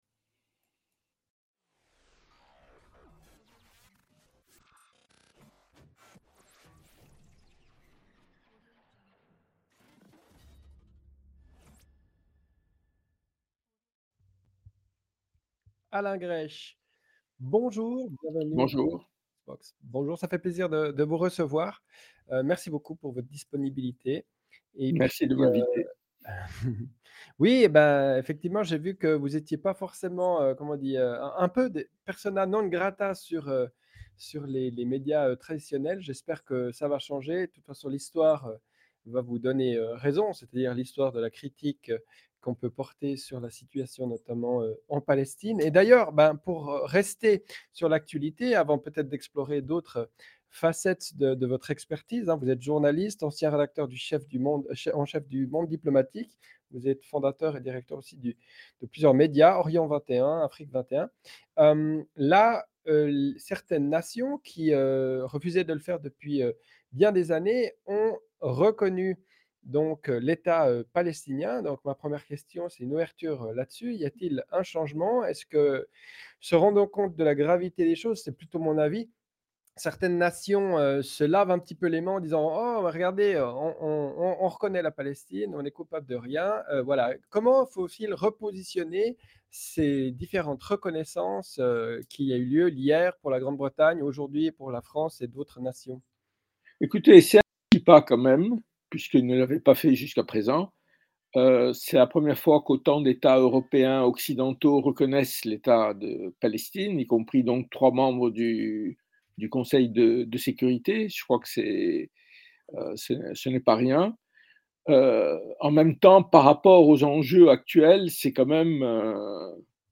Dans cet entretien exclusif, il revient sur les bouleversements du Proche-Orient, les liens entre les grandes puissances et la manière dont les récits médiatiques influencent notre compréhension des conflits.